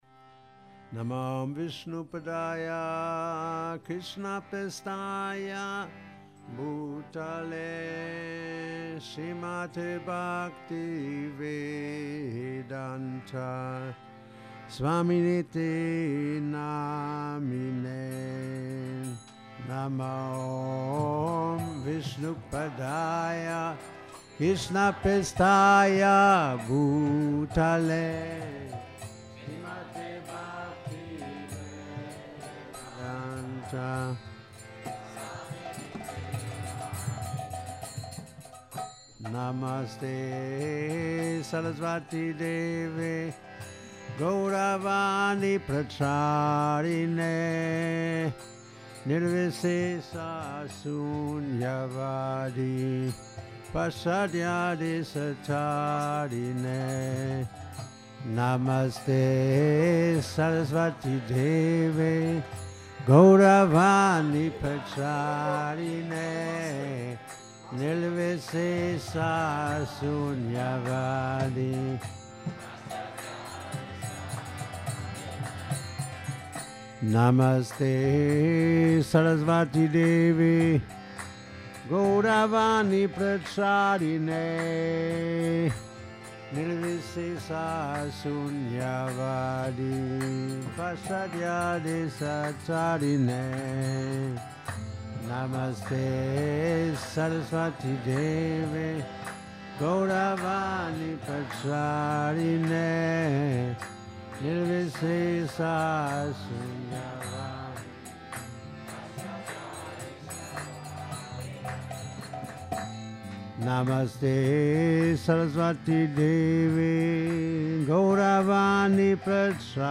Kírtan